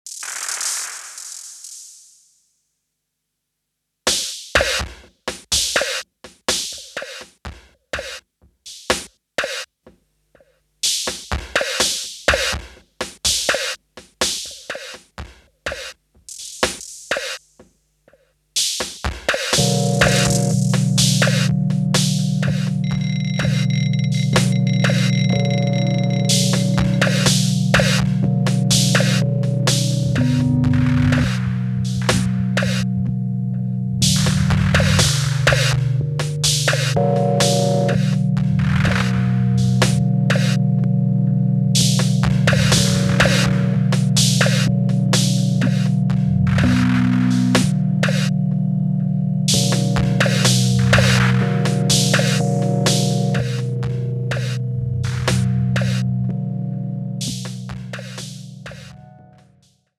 Ambient , Avant-garde , Contemporary , Krautrock